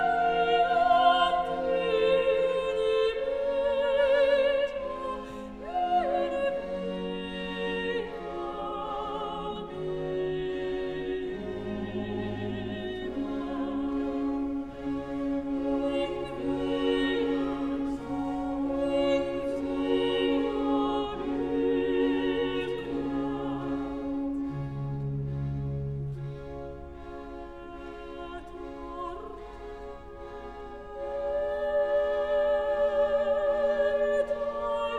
# Классика